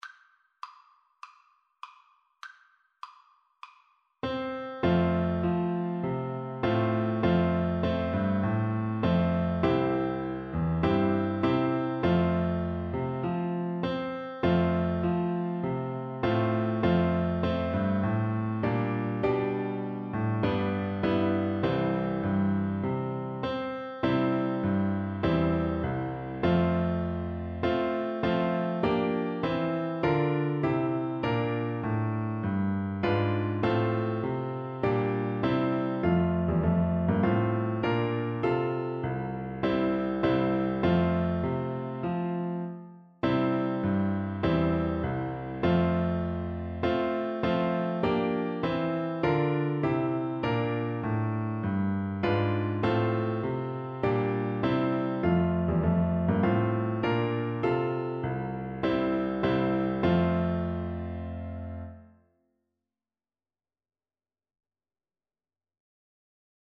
Trombone
4/4 (View more 4/4 Music)
Moderato
F major (Sounding Pitch) (View more F major Music for Trombone )
Traditional (View more Traditional Trombone Music)